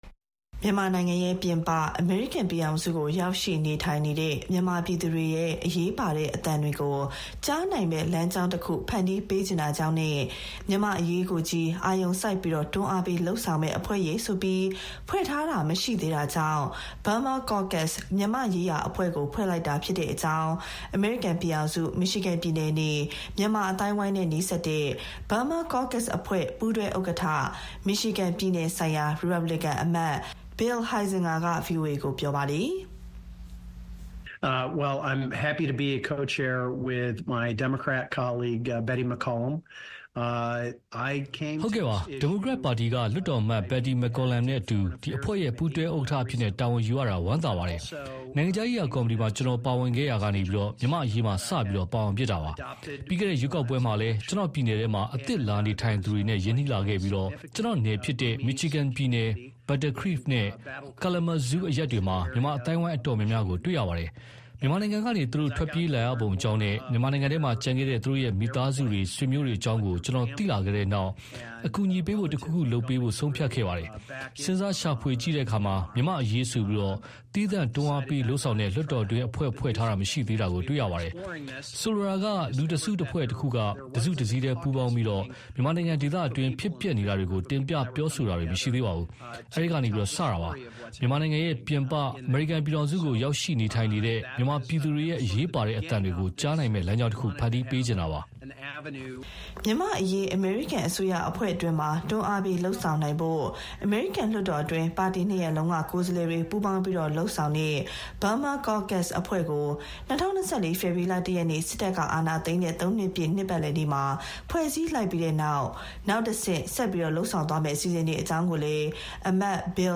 မြန်မာလူထုရဲ့အသံကို နားထောင်ဖို့ရော မြန်မာအရေးနဲ့ပတ်သက်လို့ အမေရိကန်လွှတ်တော်အတွင်း အာရုံစိုက်တွန်းအားပေးဆောင်ရွက်နိုင်ဖို့ပါ ရည်ရွယ်ပြီး Burma Caucus မြန်မာ့အရေးအဖွဲ့ကို ဖွဲ့စည်းခဲ့တာဖြစ်ကြောင်း Burma Caucus ရဲ့ပူးတွဲဥက္ကဋ္ဌ၊ ရီပတ်ဘလစ်ကန်အောက်လွှတ်တော်အမတ် Bill Huizenga က ဗွီအိုအေကို ပြောပါတယ်။